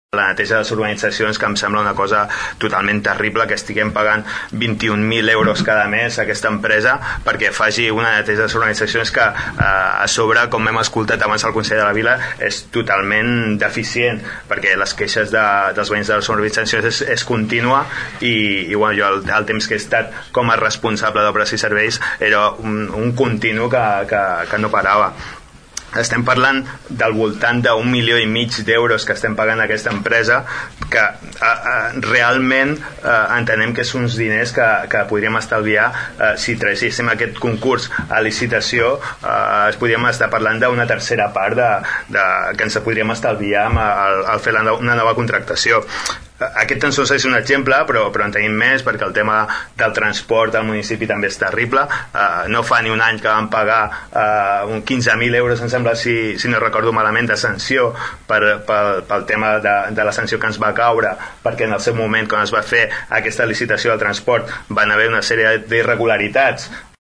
Salvador Giralt, de Som Tordera, va agrair la feina del regidor Martín per tirar endavant aquesta moció conjunta. Va posar el contracte d’escombraries amb l’empresa Alum i el del transport com a exemples d’una gestió deficient.